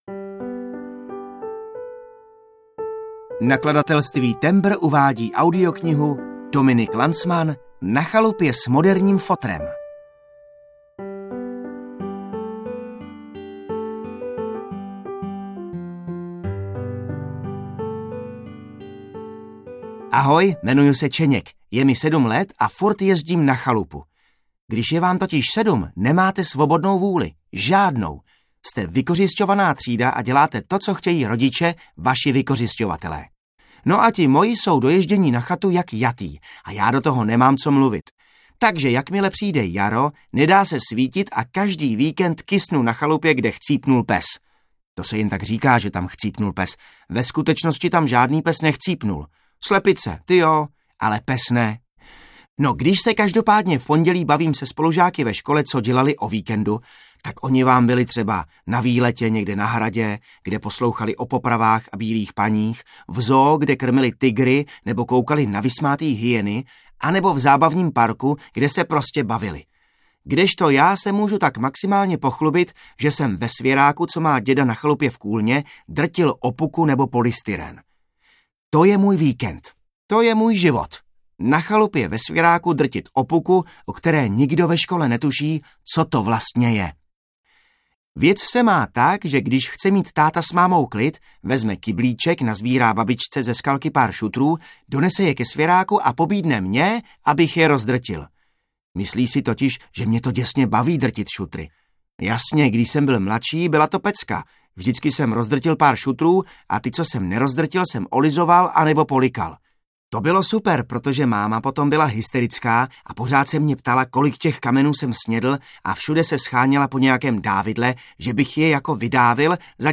Na chalupě s moderním fotrem audiokniha
Ukázka z knihy
• InterpretJan Maxián